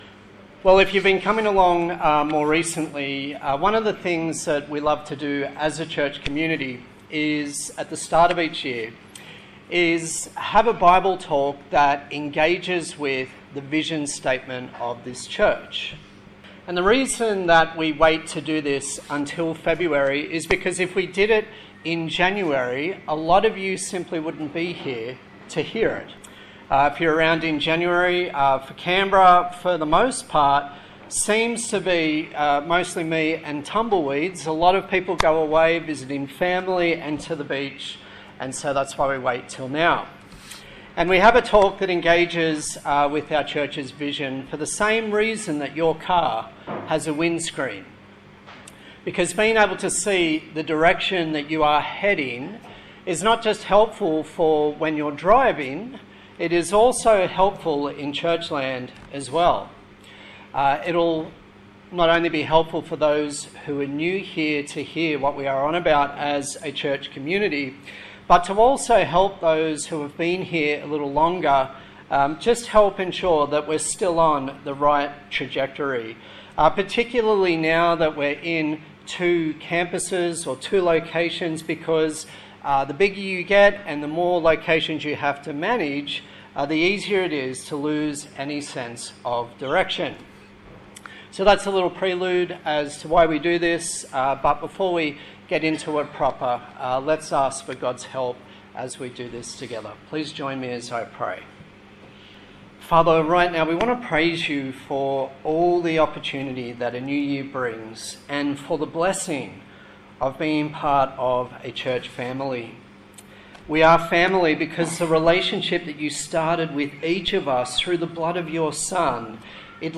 Colossians Passage: Colossians 1 Service Type: Sunday Morning